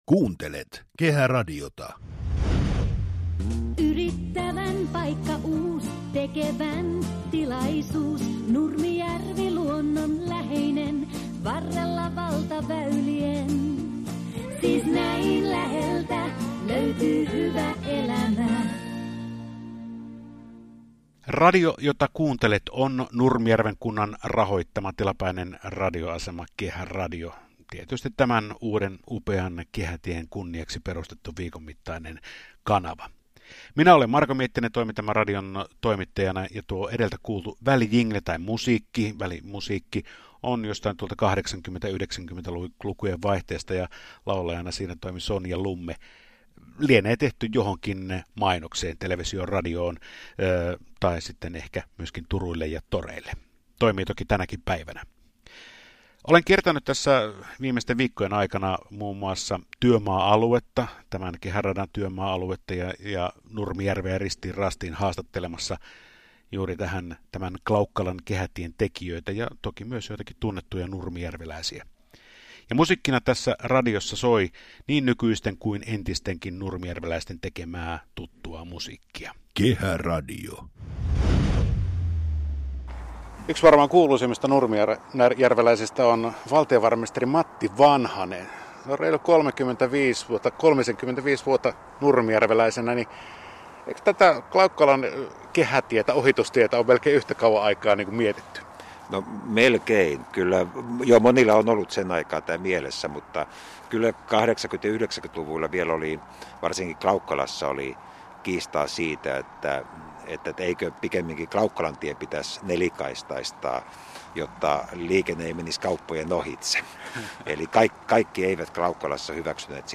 Lisäksi kehätiellä ajaneet saivat kokea uuden tien autoradioissaan Kehäradiosta, joka soi tiellä koko avajaisviikon ajan.